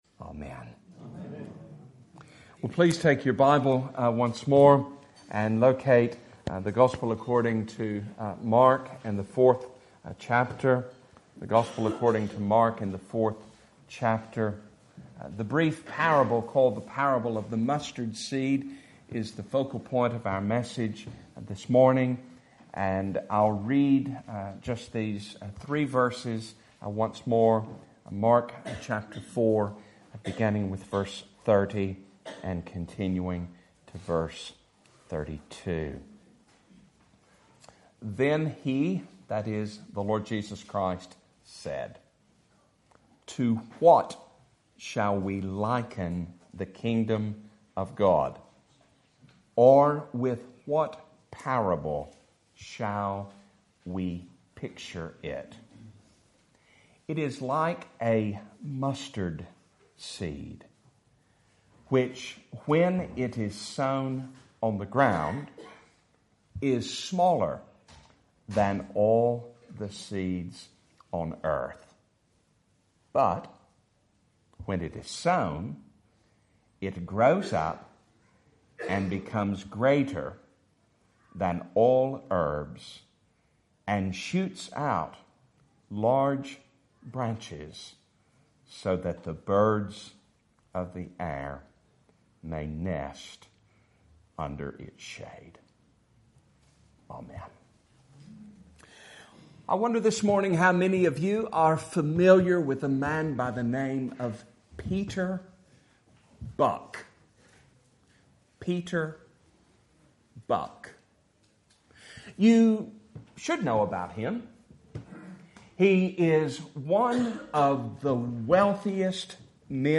Passage: Mark 4:30-32 Service Type: Sunday Morning